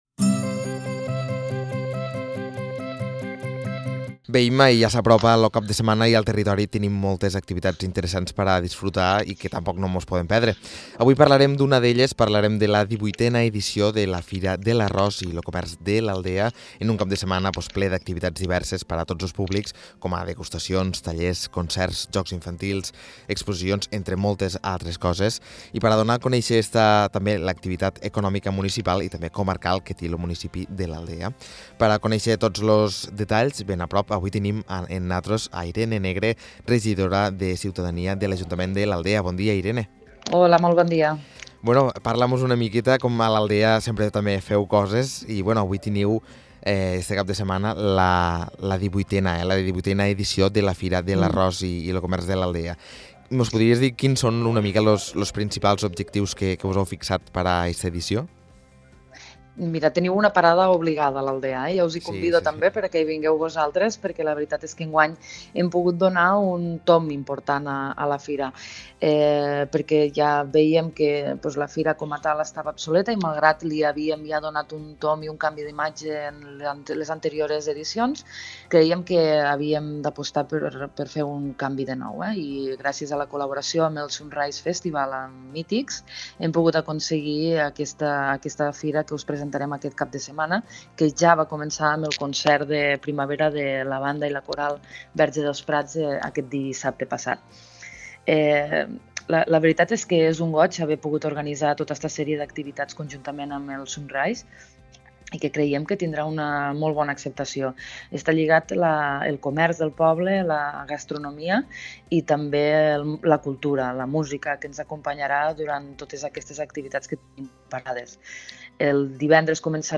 Amb la regidora de Ciutadania i Cultura de l’Ajuntament de l’Aldea, Irene Negre, parlem sobre les novetats d’aquesta nova edició de la Fira de l’Arròs i del Comerç que tindrà lloc aquest cap de setmana a l’Aldea.